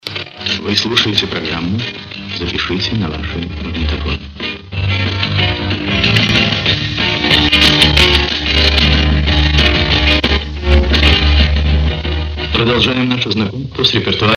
Genre:Jazz, Pop, Classical, Stage & Screen
Style:Easy Listening